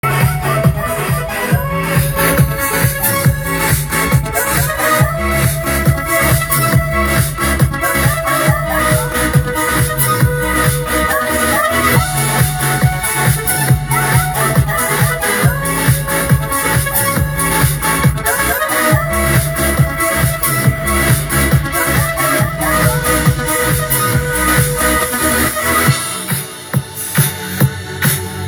w Muzyka elektroniczna